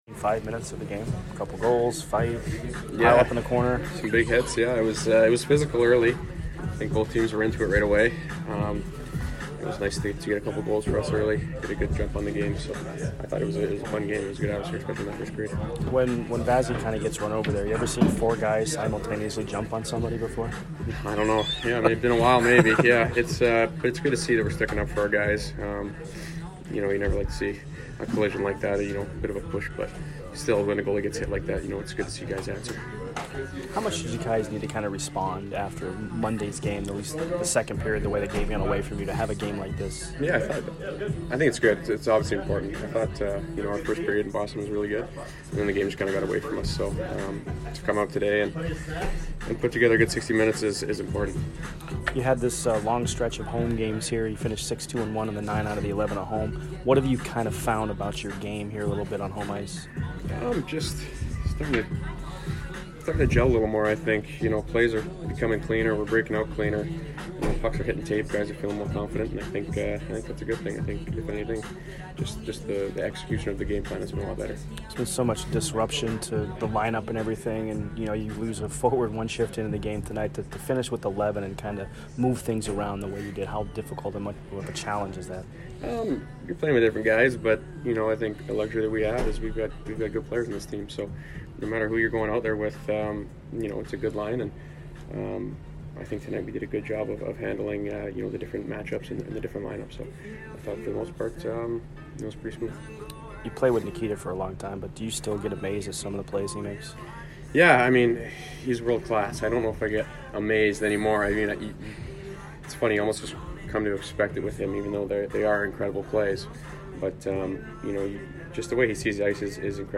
Brayden Point Post Game 11/25/22 vs STL